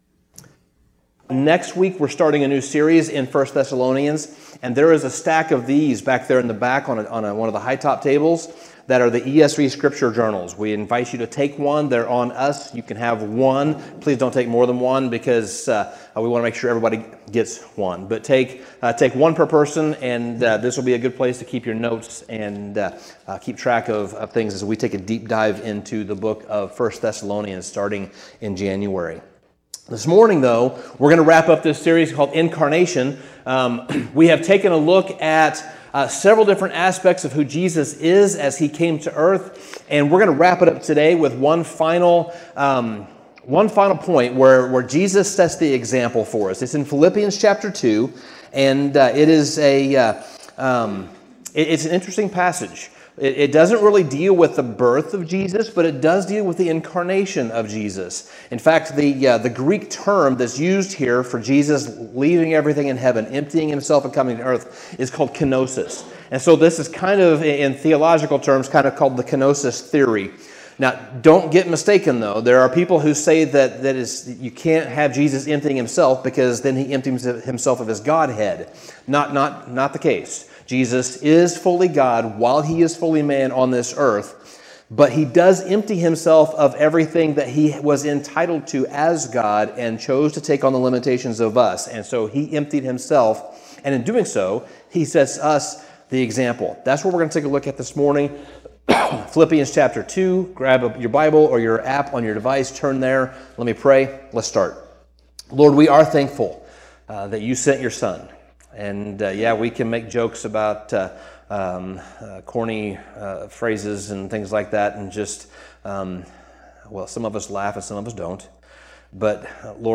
Sermon Summary In Philippians 2, Paul presents the Incarnation of Christ as the ultimate example of humility and selflessness.